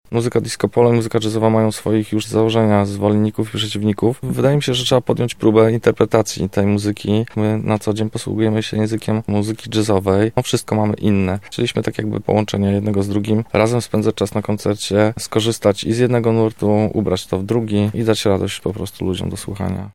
Disco polo w wykonaniu zespołu jazzowego.